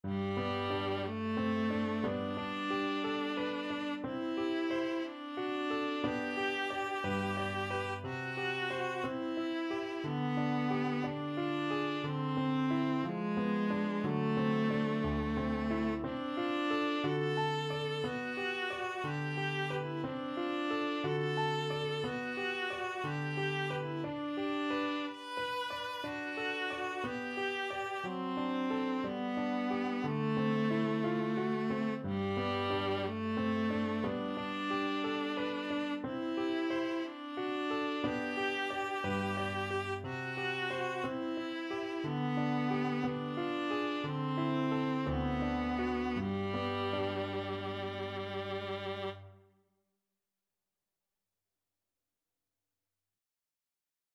Classical Granados, Enrique Dedicatoria (from Cuentos de la Juventud, Op.1) Viola version
Viola
~ = 60 Andantino (View more music marked Andantino)
2/4 (View more 2/4 Music)
G major (Sounding Pitch) (View more G major Music for Viola )
Classical (View more Classical Viola Music)